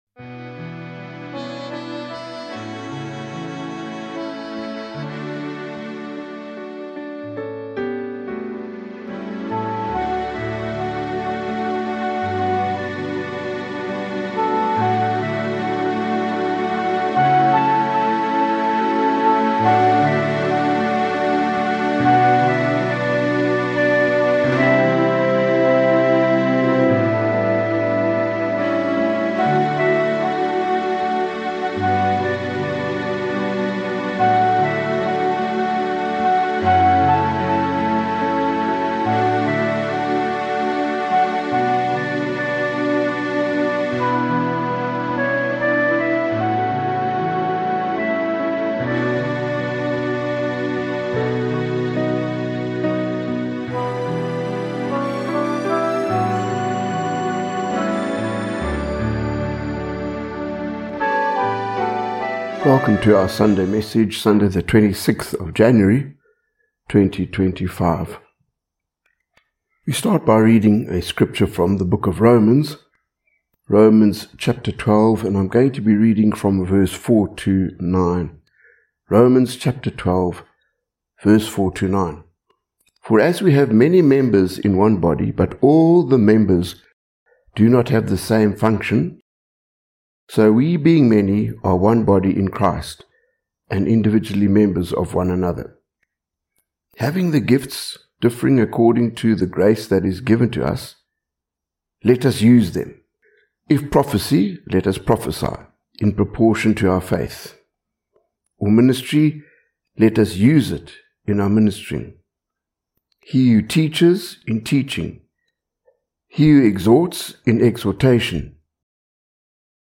1 Sunday message: "Unction to function." 28:47